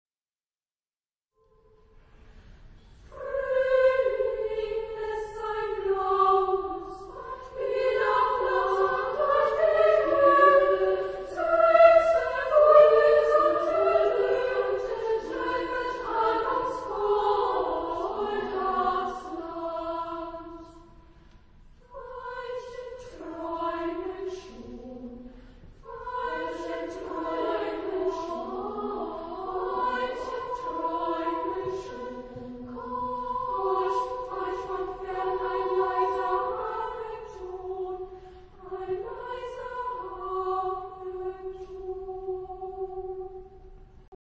Epoque: 20th century  (1900-1949)
Genre-Style-Form: Choir ; Secular
Type of Choir:  (2 voices )
Discographic ref. : 7. Deutscher Chorwettbewerb 2006 Kiel